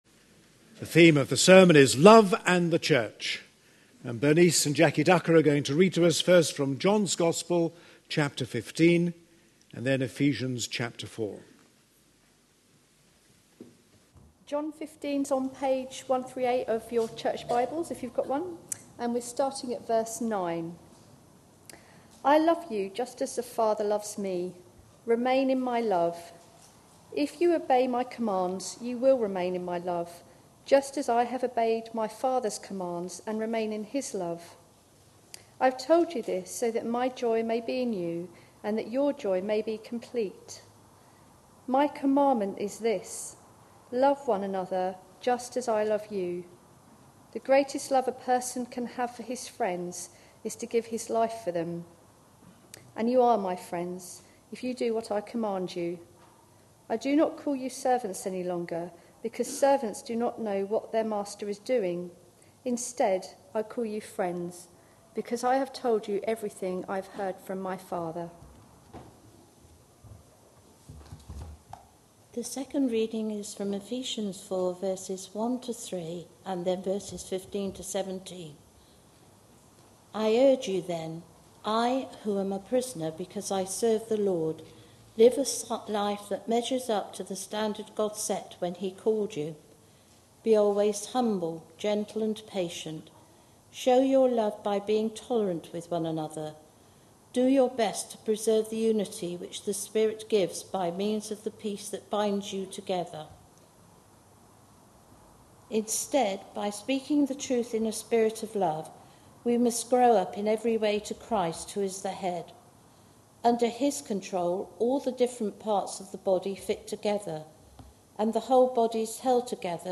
A sermon preached on 26th February, 2012, as part of our Looking For Love (10am Series) series.